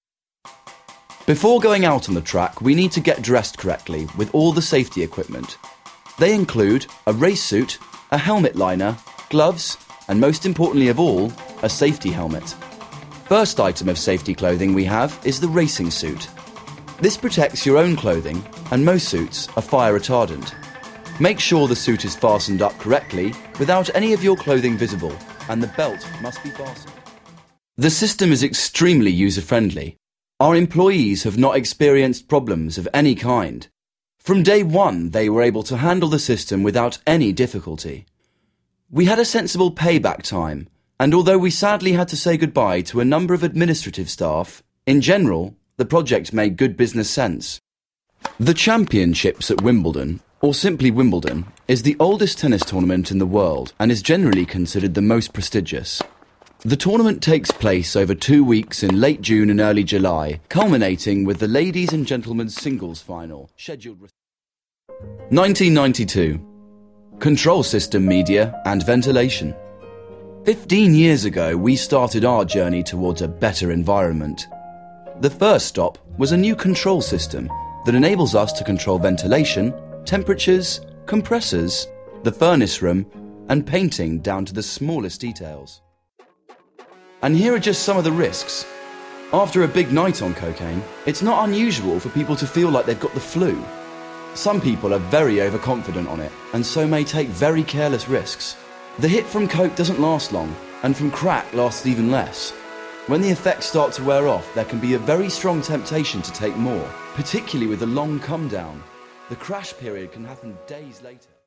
Englisch (UK)
Männlich